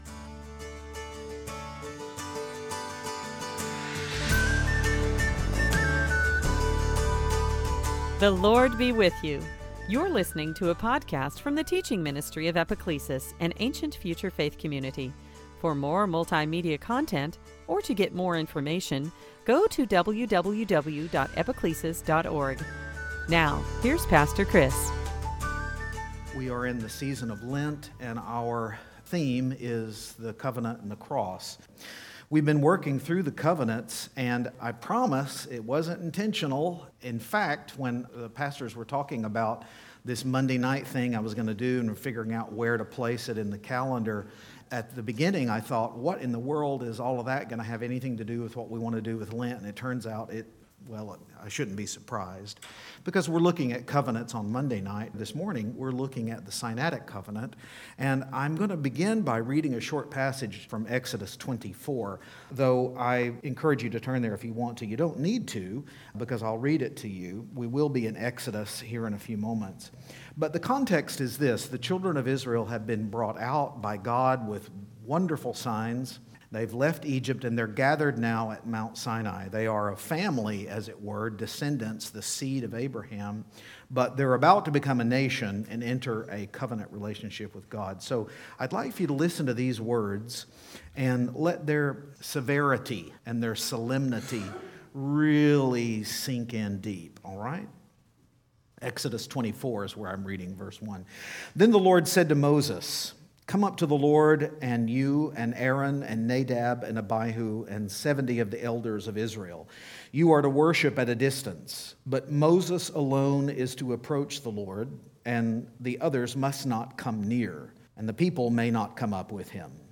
Series: Sunday Teaching
Service Type: Lent